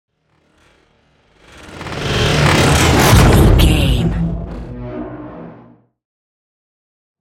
Sci fi vehicle whoosh and hit
Sound Effects
dark
futuristic
intense
woosh to hit